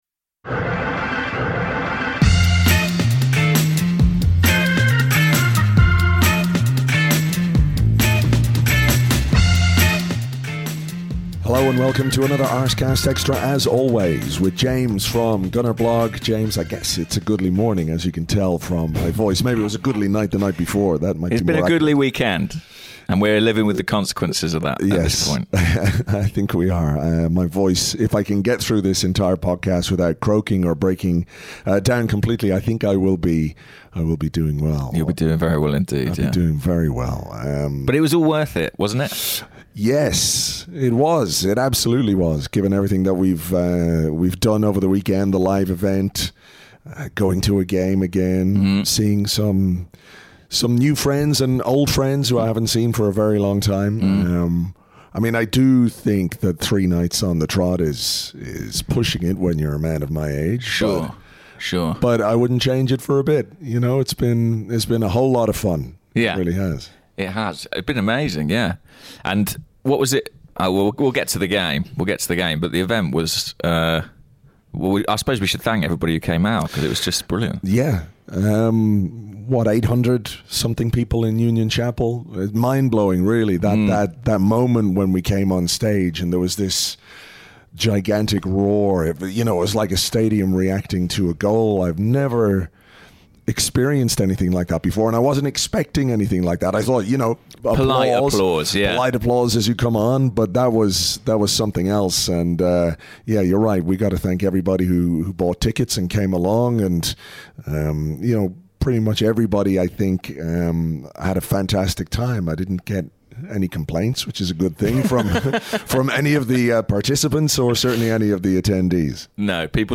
Note: My voice is a bit croaky after three nights out in a row in London, so apologies for that!